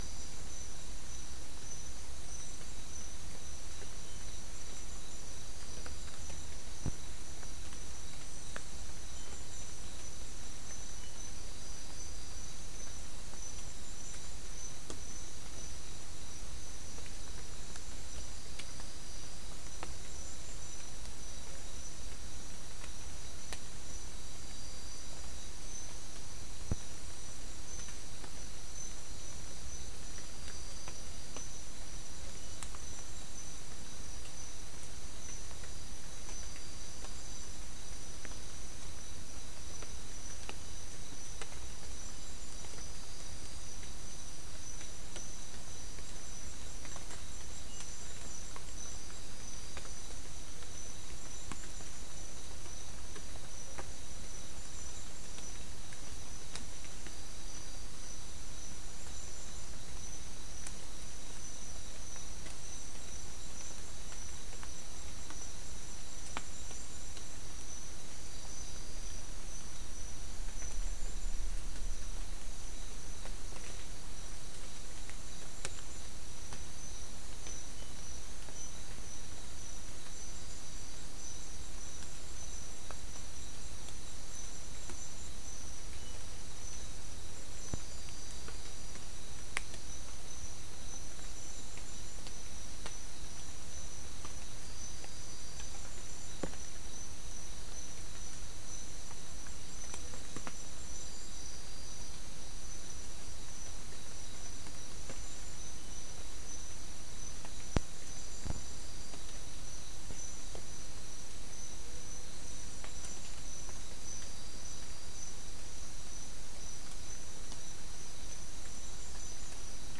Non-specimen recording: Soundscape Recording Location: South America: Guyana: Mill Site: 4
Recorder: SM3